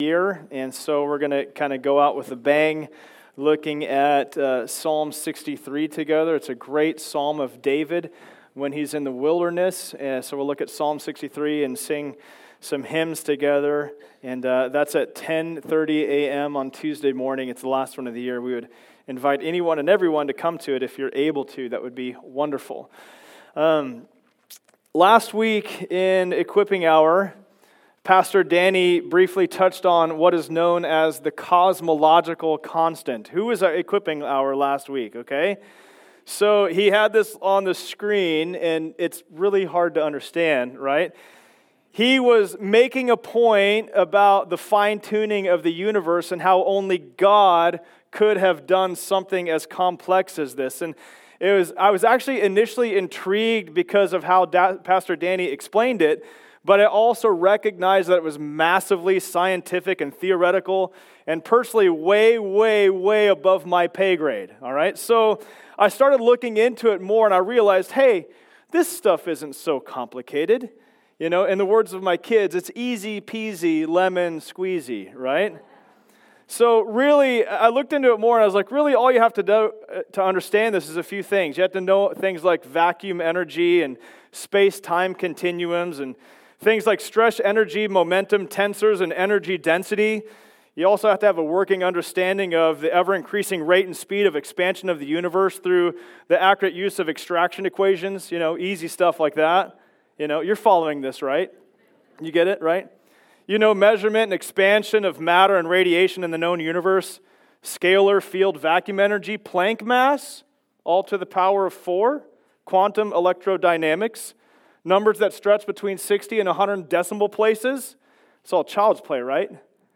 From Knowing to Doing Service Type: Sunday Service Download Files Notes « When Desires Turn Into Demands The Pathway to Life is…